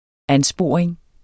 Udtale [ ˈanˌsboˀɐ̯eŋ ]